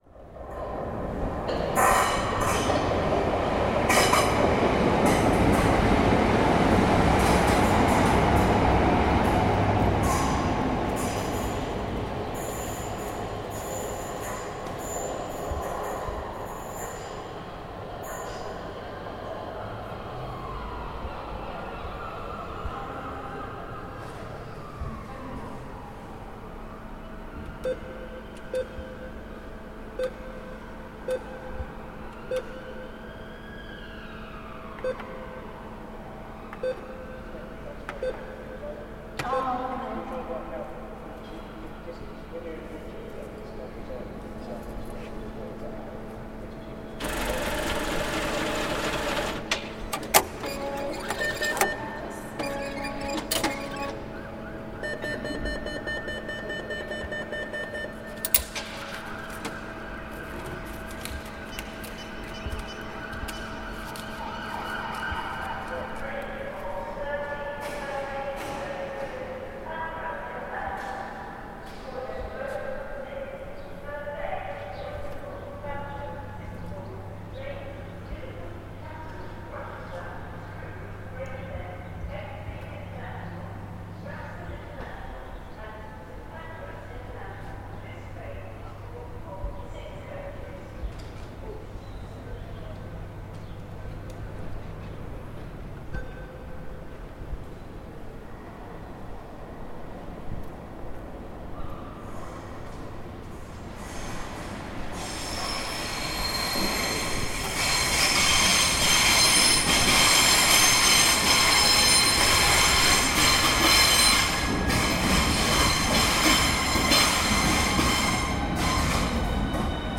Margate station sounds
The main rail station in Margate - cavernous reverb, train arrivals and cash machines.
Part of the Cities and Memory Margate sound map for Dreamland.